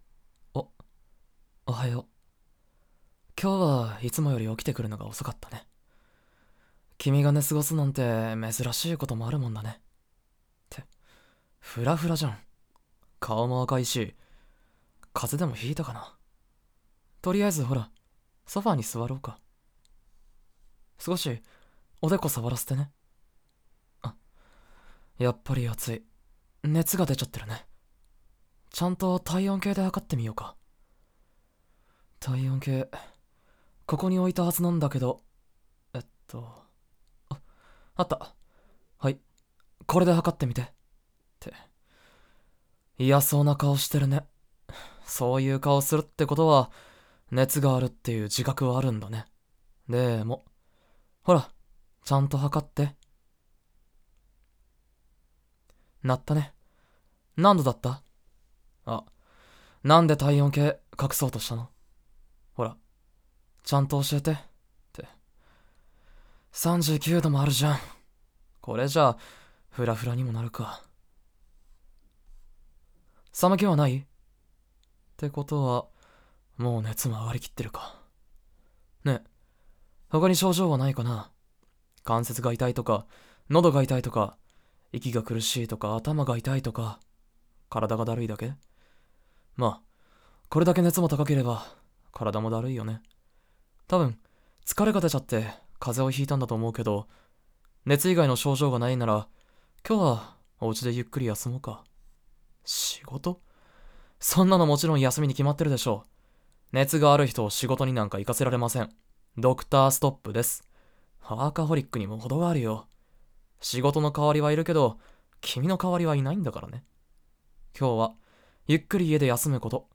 纯爱/甜蜜 日常/生活 同居 纯爱 女性视角 医生 女主人公 同居 女性向 乙女向